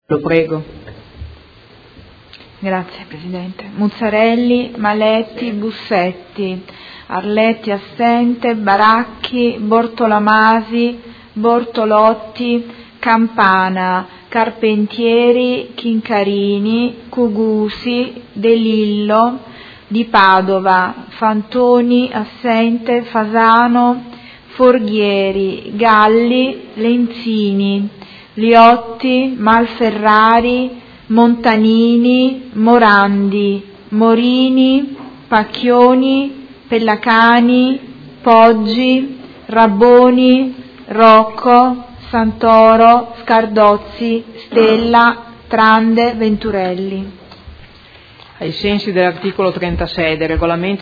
Seduta del 06/04/2017 Appello.
Segretaria